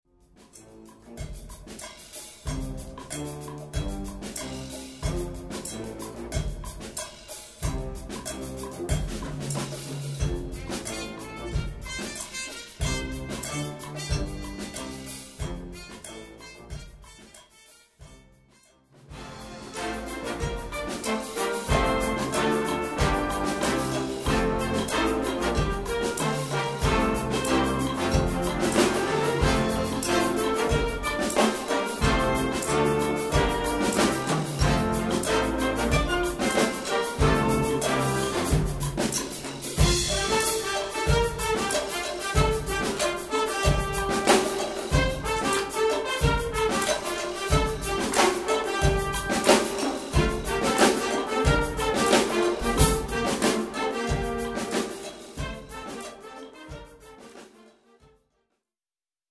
Gattung: Entertainment
A4 Besetzung: Blasorchester PDF